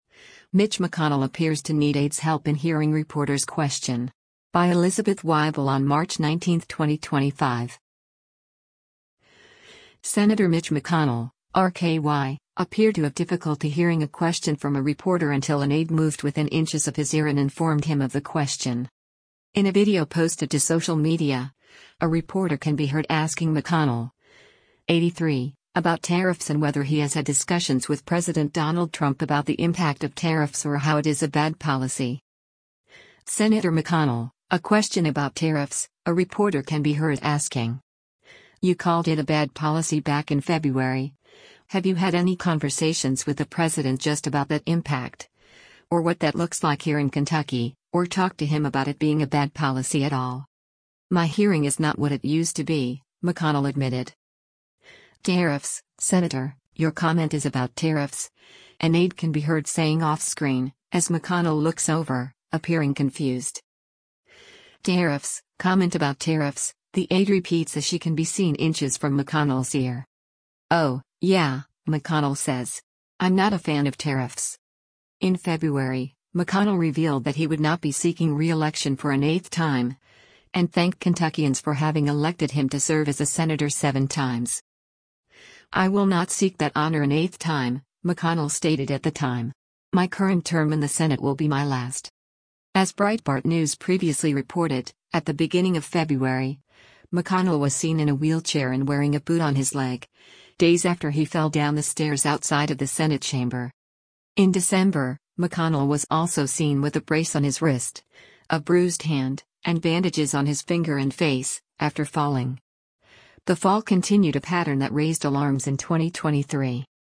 Sen. Mitch McConnell (R-KY) appeared to have difficulty hearing a question from a reporter until an aide moved within inches of his ear and informed him of the question.
In a video posted to social media, a reporter can be heard asking McConnell, 83, about tariffs and whether he has had discussions with President Donald Trump about the impact of tariffs or how it is “a bad policy.”
“Tariffs, Senator, your comment is about tariffs,” an aide can be heard saying off screen, as McConnell looks over, appearing confused.